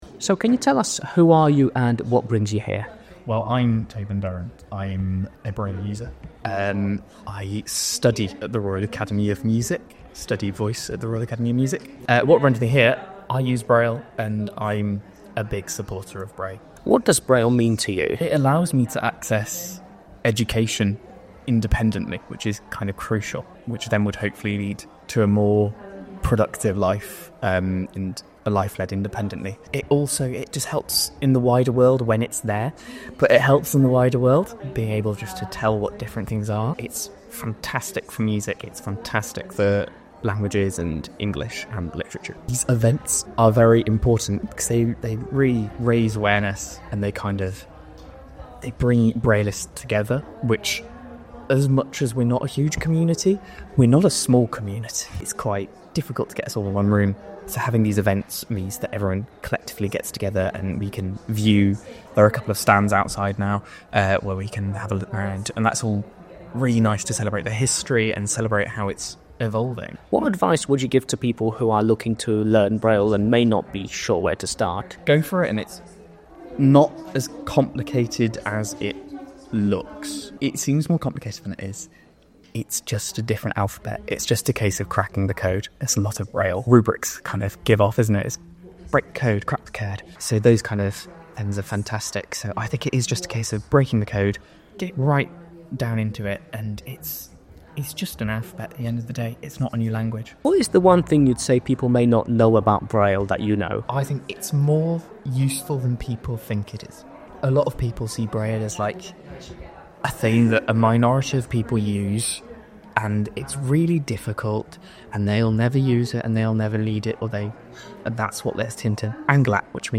heard from two people learning braille and using it for their studies